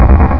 game-source/ParoxysmII/sound/items/health1.wav at a86c777218c3b32c2c21d78278b1f38d56380ce7